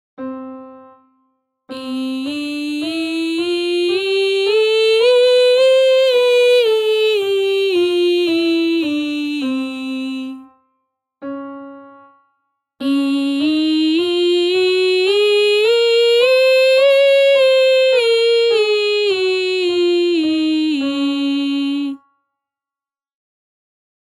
Stemtesten Bereik
met Stemtest 1 Vrouw
Stemtest-vrouw1.mp3